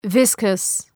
{‘vıskəs}